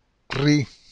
Their sound is achieved strongly “vibrating” tongue against the front of the palate: